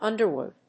/ˈʌndɝˌwʊd(米国英語), ˈʌndɜ:ˌwʊd(英国英語)/